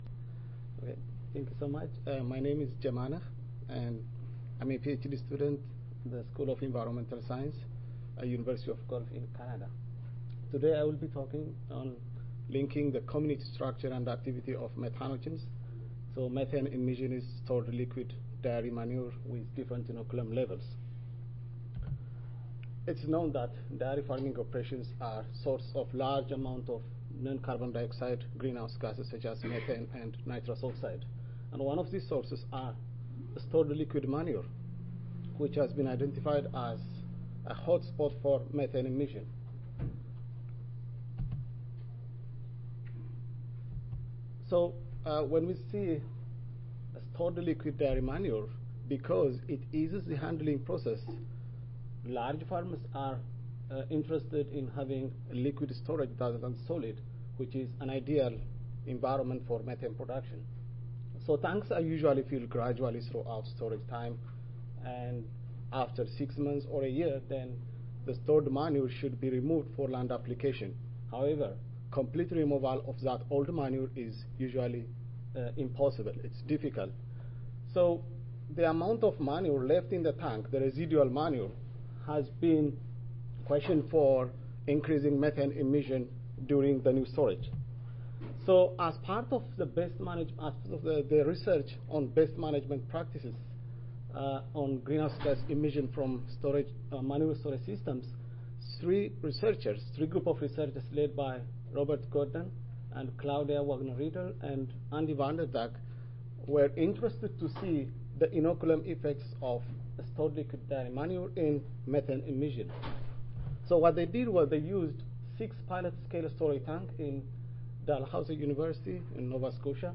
See more from this Division: ASA Section: Environmental Quality See more from this Session: Greenhouse Gas Emissions from Integrated-Crop Livestock System Oral